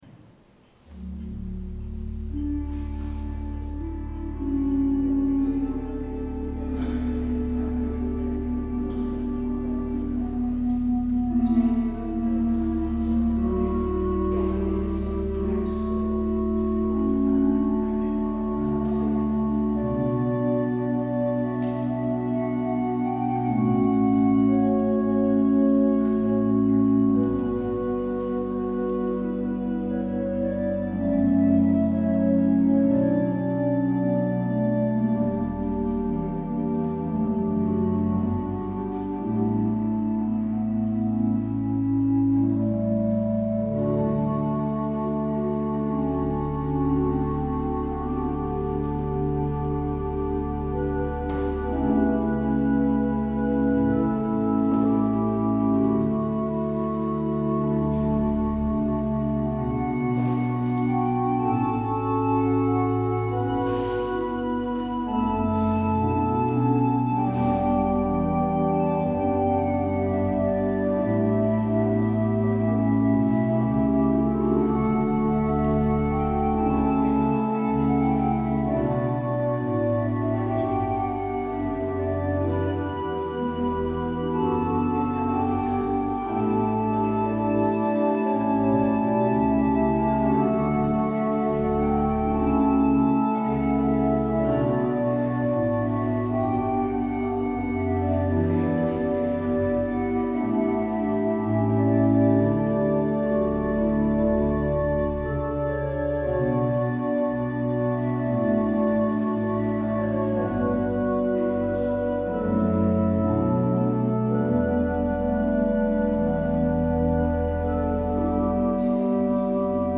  Luister deze kerkdienst hier terug
Het openingslied is NLB 280: 1, 2 en 5.
Als slotlied hoort u NLB 416.